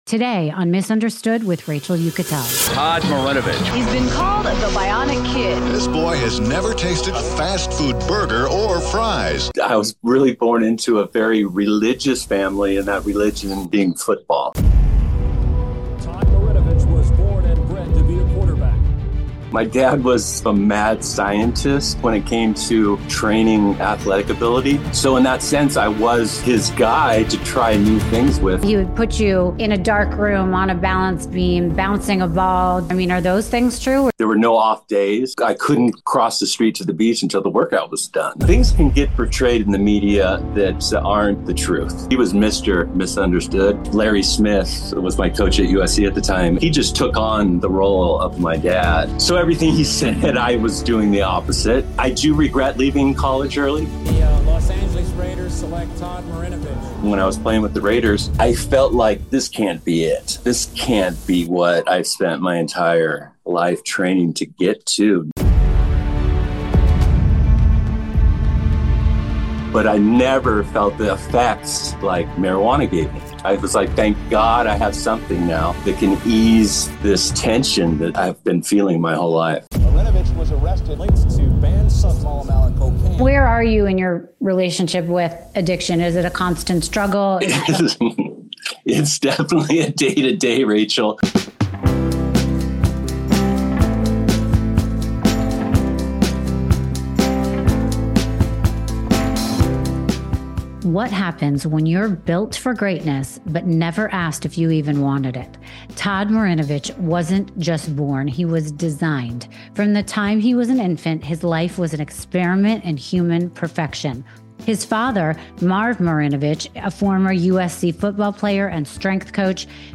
In this powerful episode of Miss Understood, Rachel Uchitel sits down with former NFL quarterback Todd Marinovich, once dubbed “The Test Tube Athlete.” Todd opens up about the truth behind his controversial upbringing, his battles with addiction, and the long journey toward self-forgiveness and peace.